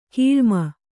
♪ kīḷmana